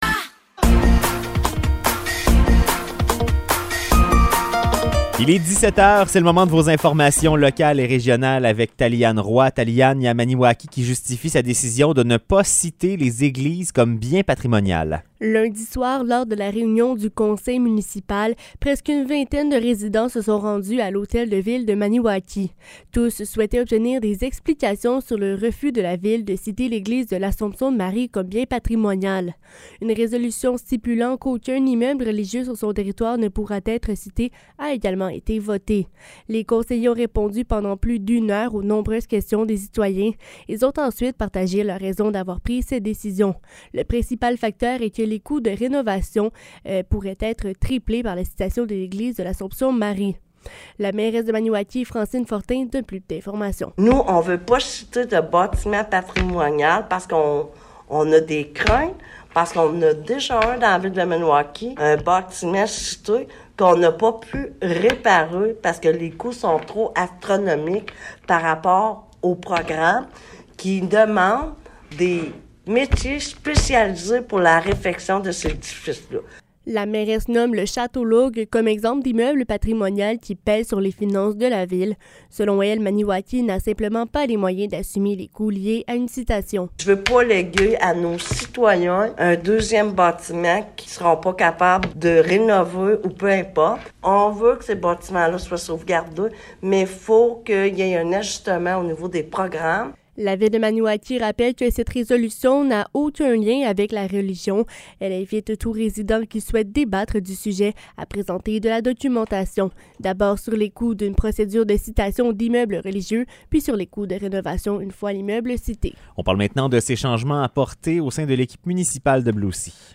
Nouvelles locales - 7 juin 2023 - 17 h